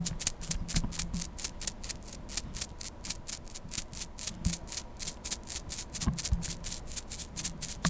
R - Foley 183.wav